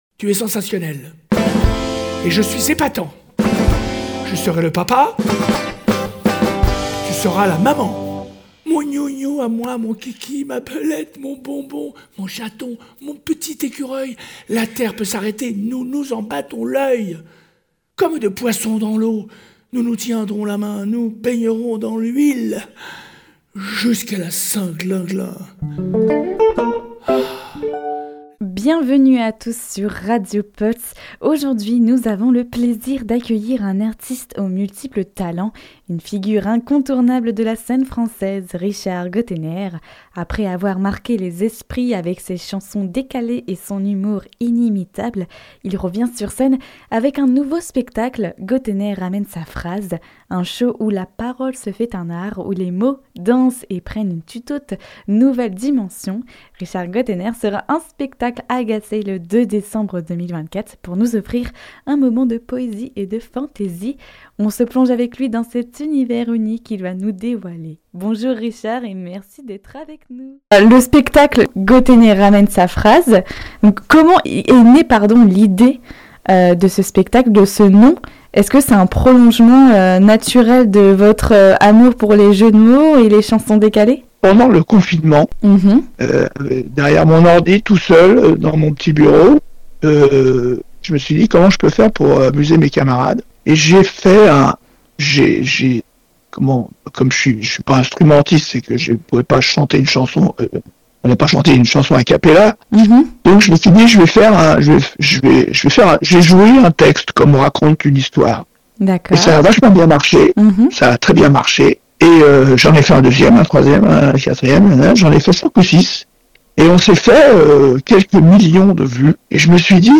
Nous avons le plaisir de recevoir Richard Gotainer, figure incontournable de la chanson française, à l’occasion de son concert prévu le mardi 3 décembre 2024 à la salle Le Tahiti à Gacé (Orne).Ce spectacle, organisé en partenariat entre la Communauté de Communes des Vallées d’Auge et du Merlerault et C’61, promet un moment unique mêlant humour, poésie et musique. Richard Gotainer revient sur scène avec son univers inimitable, ses textes décalés et ses mélodies qui résonnent auprès de toutes les générations.